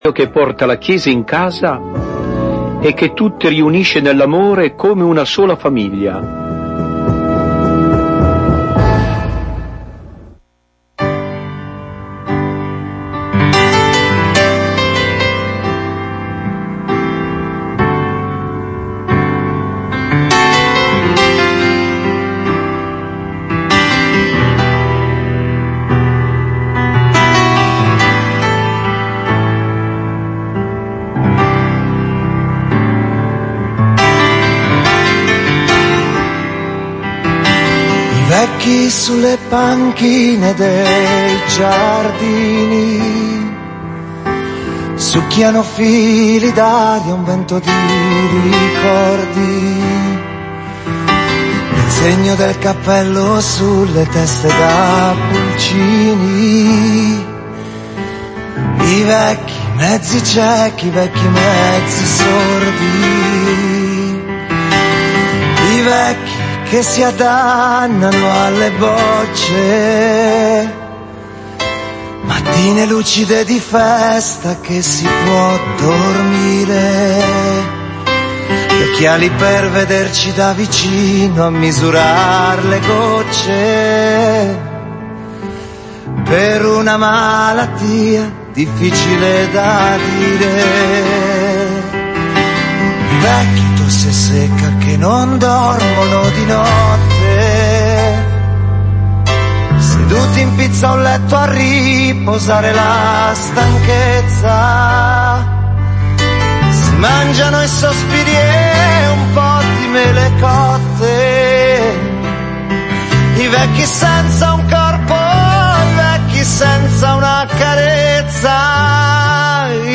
Registrazione della trasmissione di Radio Mater del 19 ottobre 2020